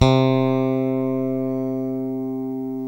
Index of /90_sSampleCDs/Roland L-CD701/BS _Jazz Bass/BS _Jazz Basses